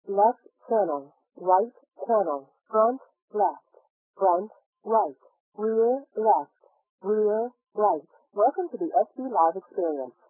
Lowering of frequencies above 1500 Hz with compression ratio=4 and offset=-100 Hz
After compression and decompression
by the VLC 8 codec at 12250 bps